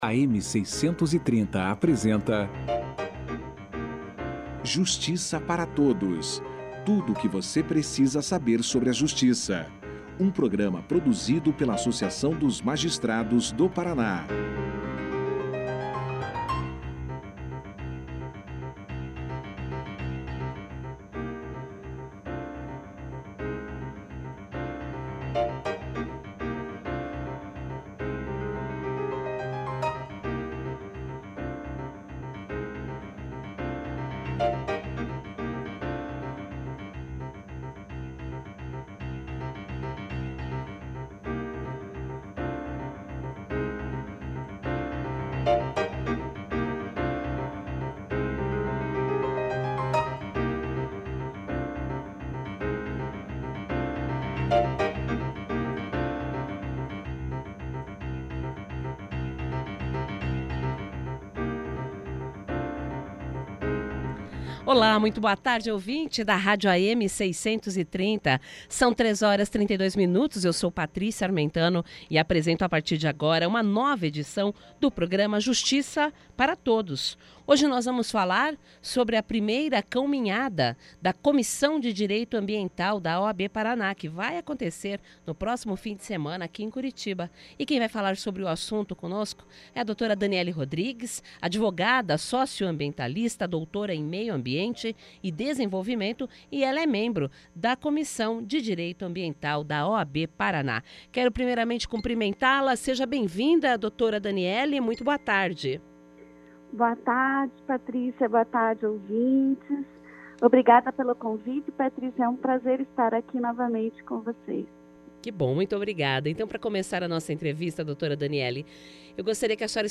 Para conscientizar a população sobre o tema, a Comissão realiza uma Cãominhada neste domingo, dia 10, aberta a todos. Confira aqui a entrevista na íntegra.